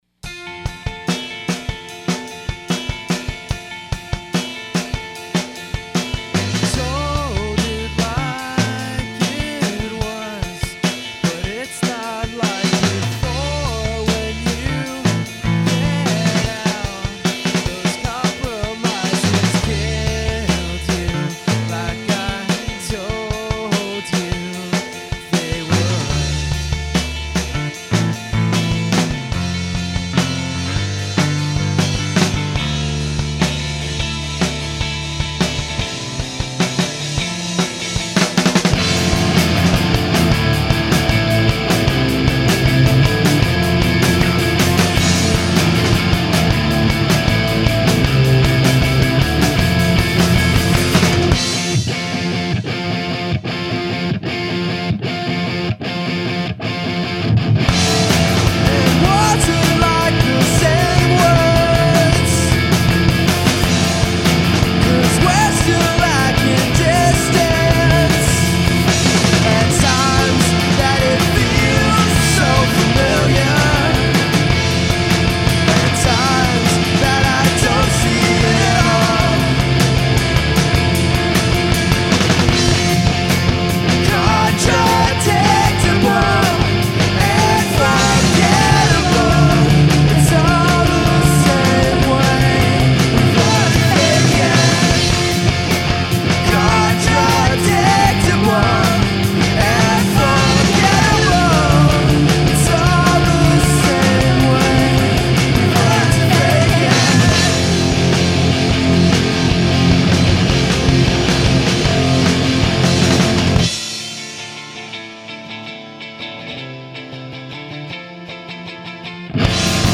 basement recordings: